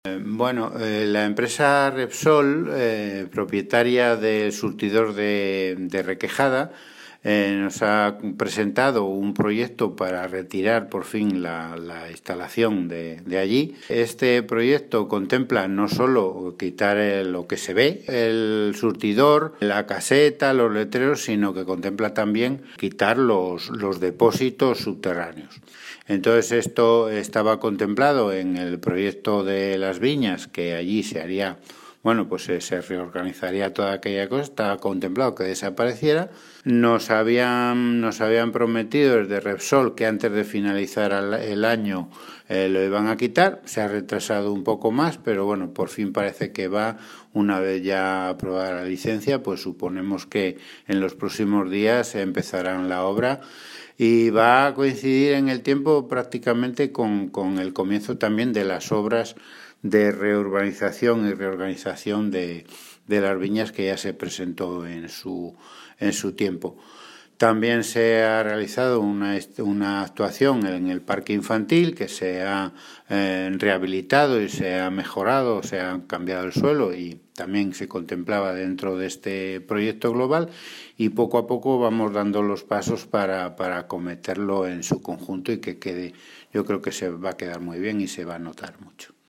El concejal de Urbanismo, Felipe Tapia, explica que la estación de servicio situada junto a la travesía de la antigua carretera nacional N-634 a escasos metros de unas viviendas, y su desmantelamiento permite al Ayuntamiento de Polanco construir una zona pública con pequeños jardines y mobiliario urbano.
Felipe-Tapia-sobre-desmantelamiento-gasolinera-Requejada.mp3